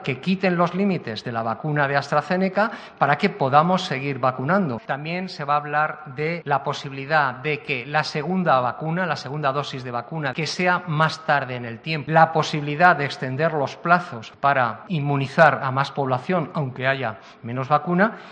Enrique Ossorio explica las alternativas de Madrid ante el retraso de la la vacuna de Janssen